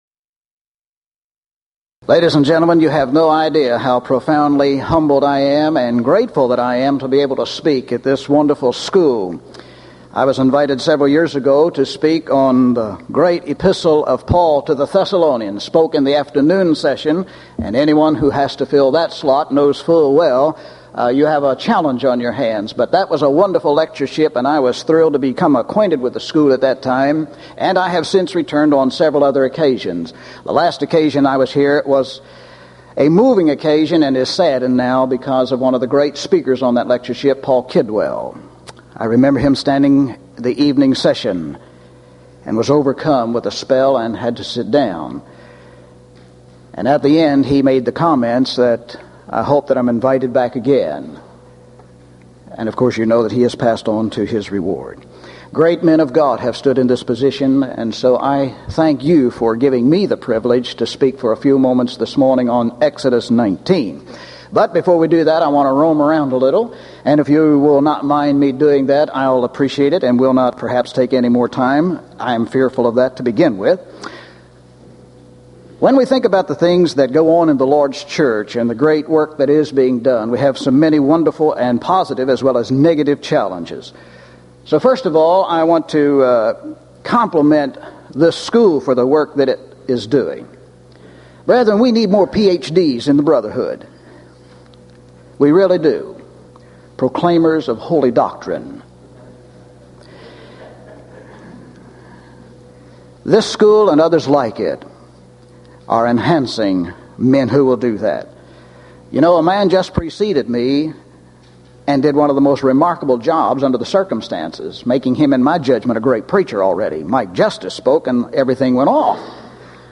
Event: 1997 East Tennessee School of Preaching Lectures Theme/Title: Studies In The Book of Exodus
lecture